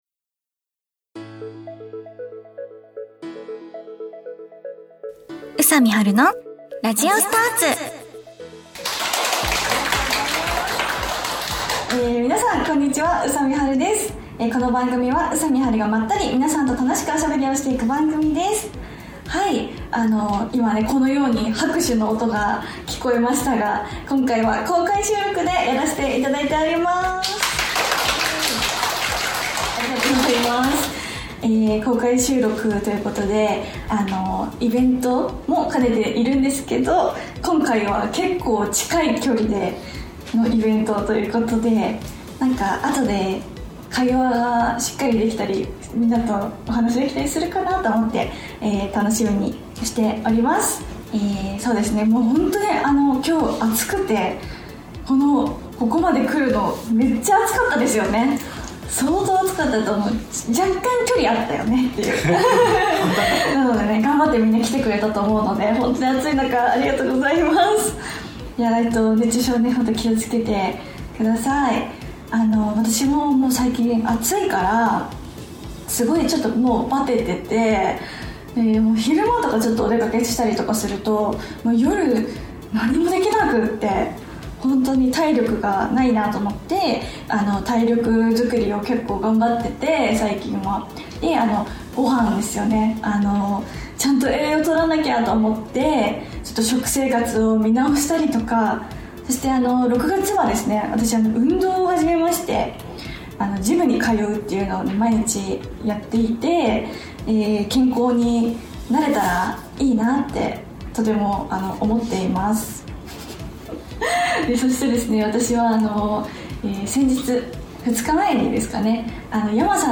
沢山の応援をいただき今年も公開収録が開催されました♪